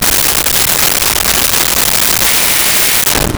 Dryer With Buzzer
Dryer with Buzzer.wav